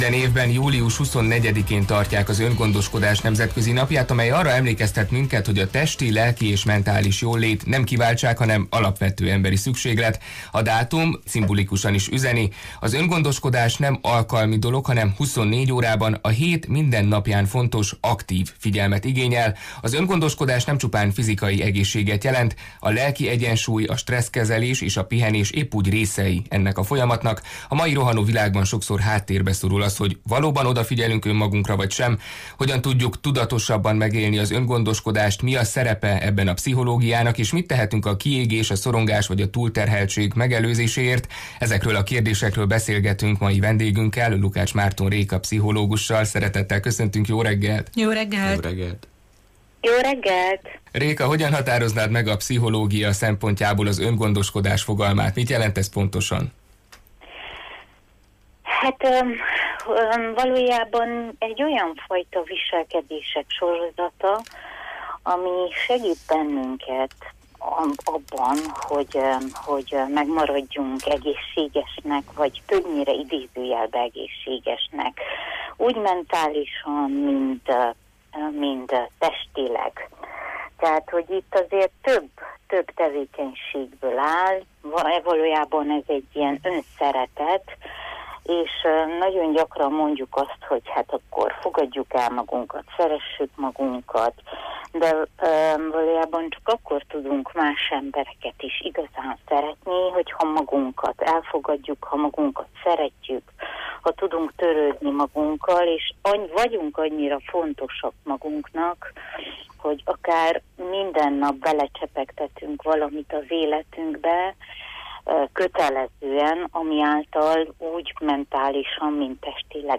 És mit tehetünk a kiégés, a szorongás vagy a túlterheltség megelőzéséért? Ezekről a kérdésekről beszélgetünk mai vendégünkkel